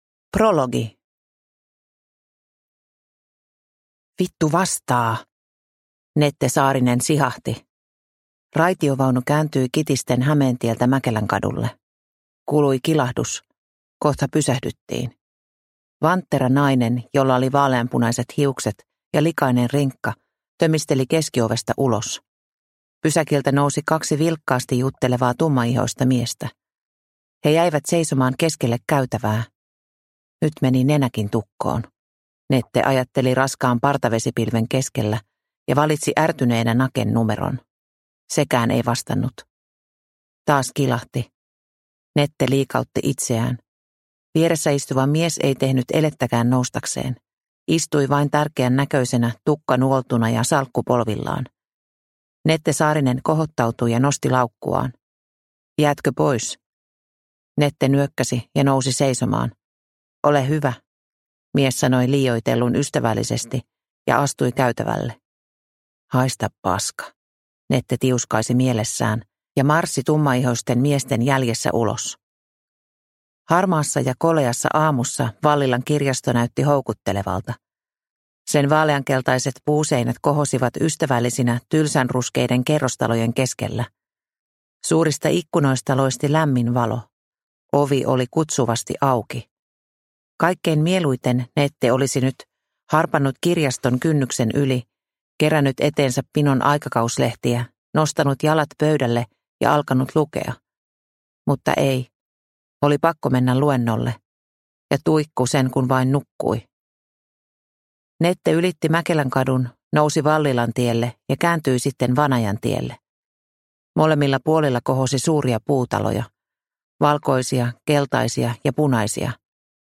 Vallilan veitsiyöt – Ljudbok – Laddas ner